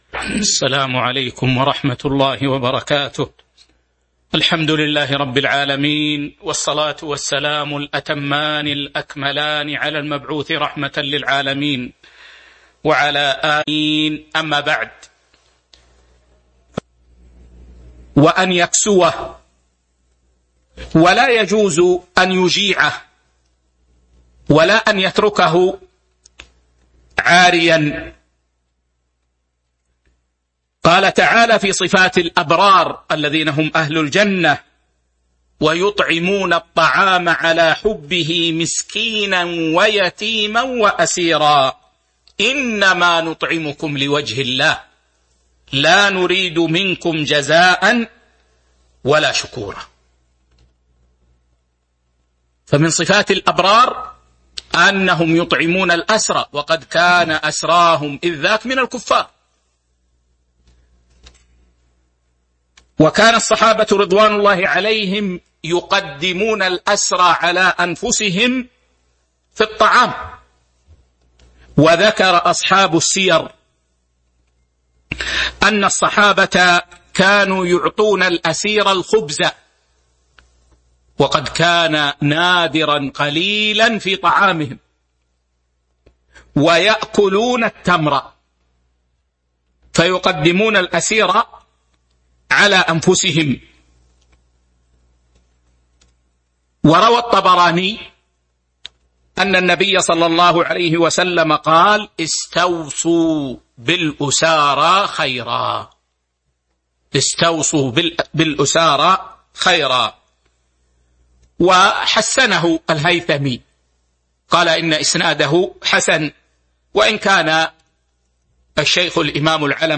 تاريخ النشر ٢٠ رجب ١٤٤٢ هـ المكان: المسجد النبوي الشيخ